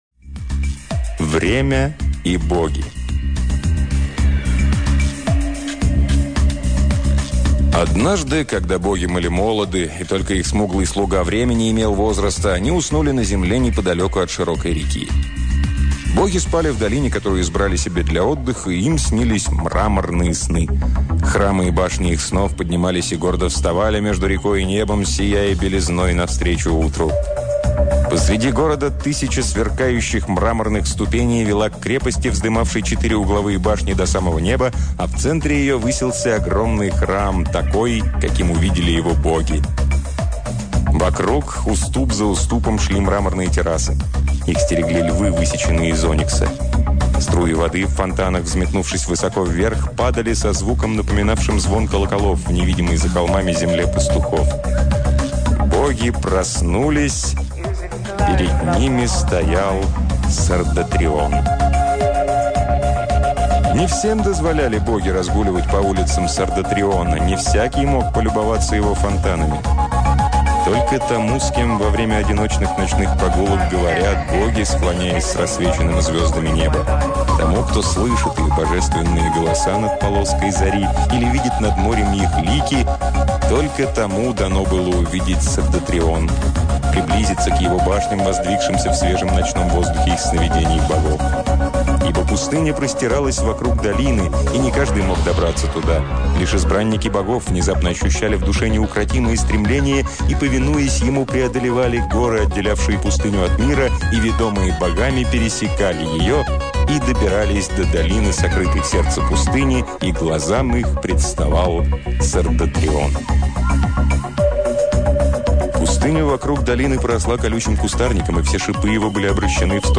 Аудиокнига Эдвард Дансейни — Время и Боги